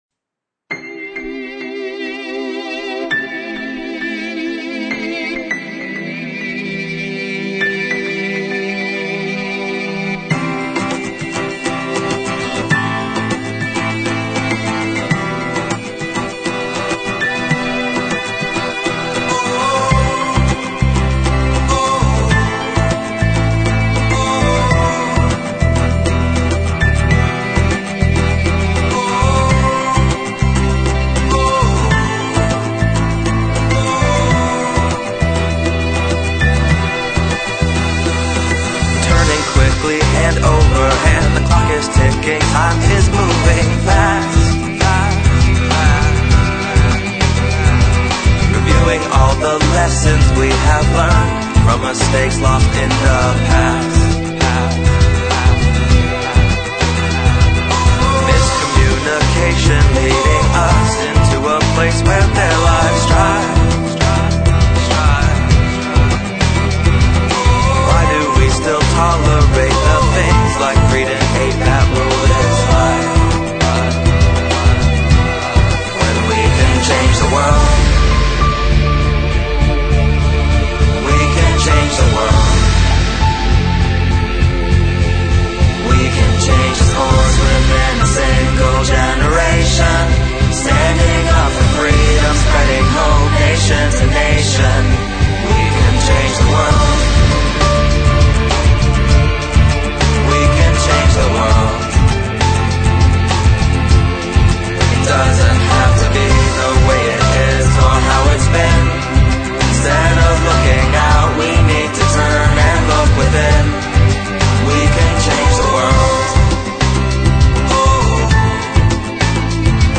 主要的乐器包括男声和女声，原声吉他，钢琴，原声和合成打击乐器，低音吉他和合成器。